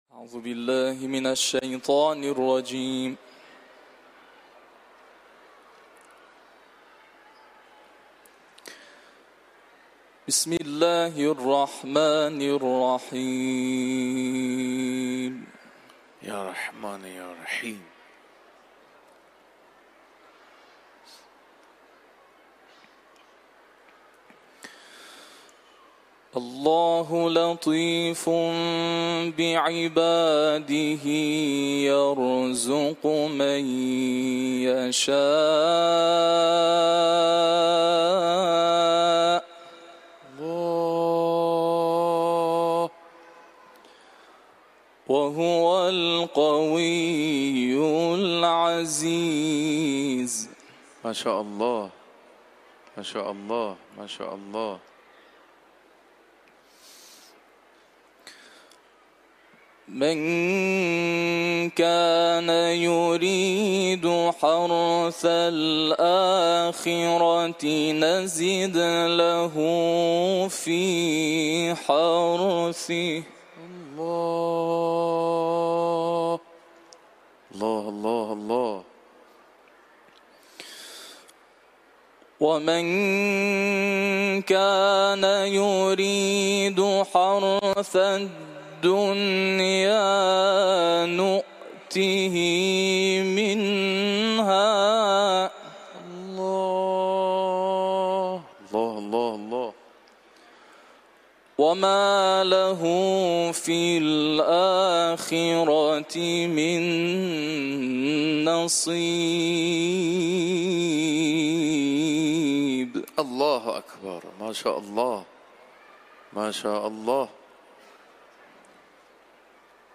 Etiketler: İranlı kâri ، Kuran tilaveti ، Şûrâ suresi